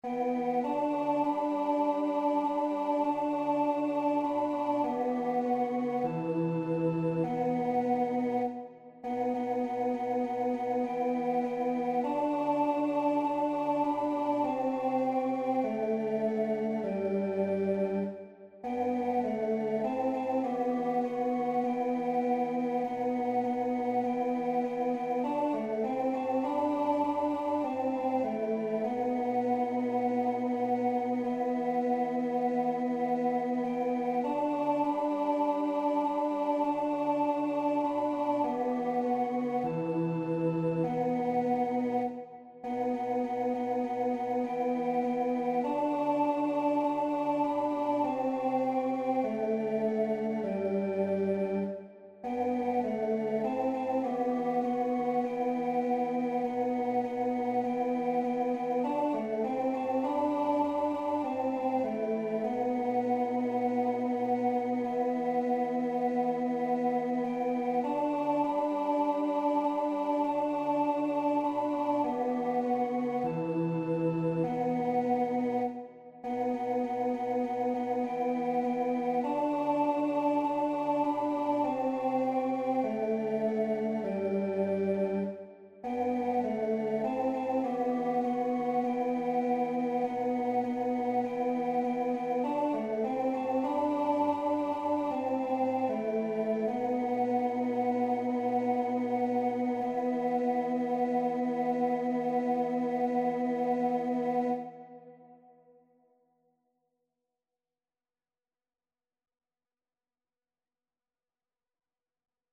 3ª Voz